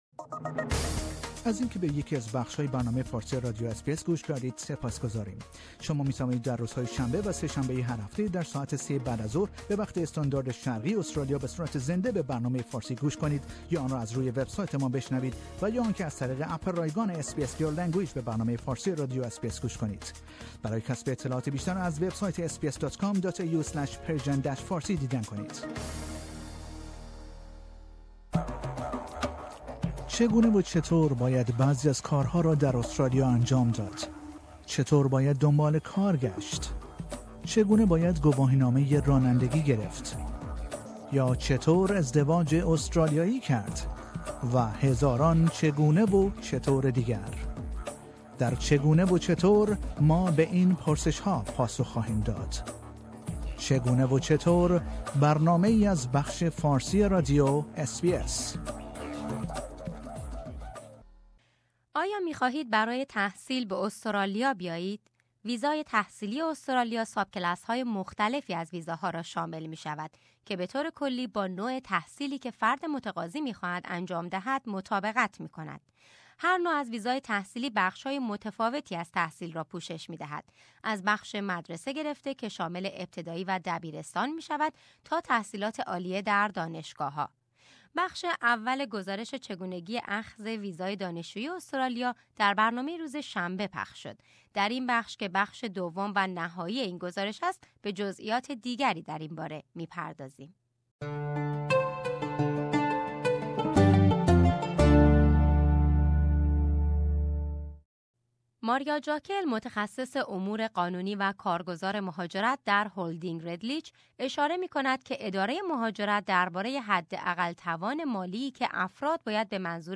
در این بخش که بخش دوم و نهایی این گزارش است، به جزئیات دیگری در این باره می پردازیم.